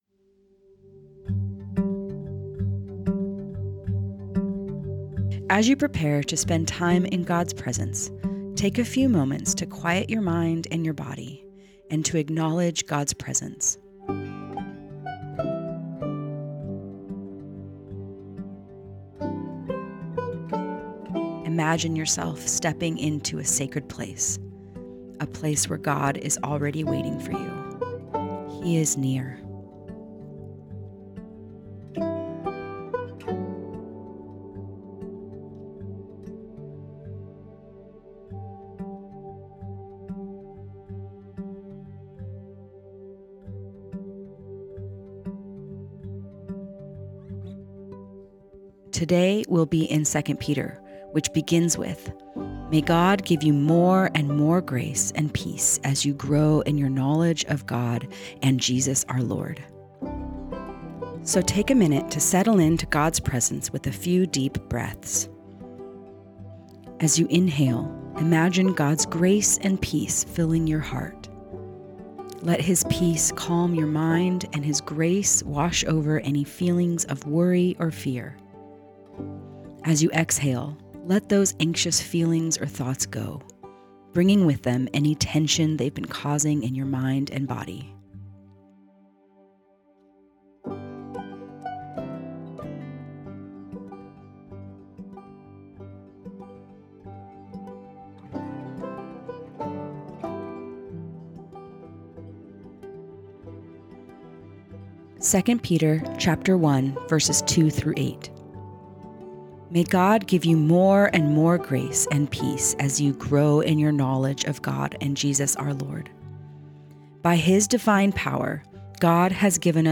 Guided Listening Practice Read As you prepare to spend time in God’s presence, take a few moments to quiet your mind and body, and to acknowledge God’s presence.